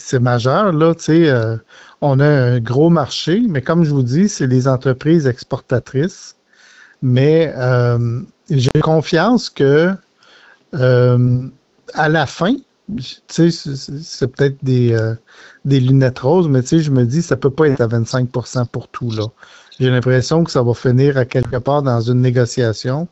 En entrevue, le député de Nicolet-Bécancour est revenu sur les derniers mois du président américain.